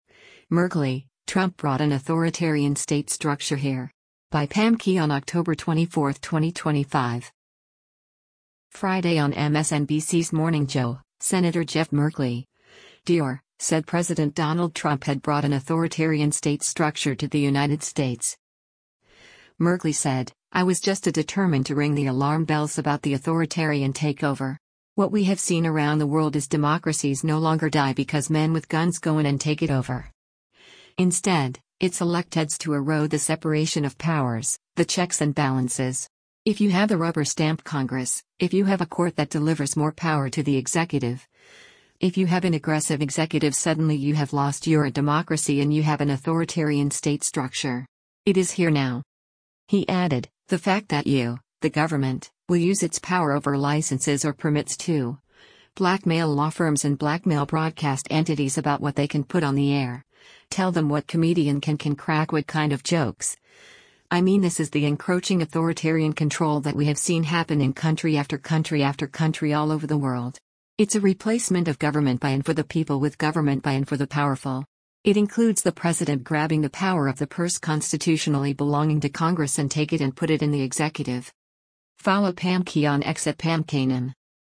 Friday on MSNBC’s “Morning Joe,” Sen. Jeff Merkley (D-OR) said President Donald Trump had brought an “authoritarian state structure” to the United States.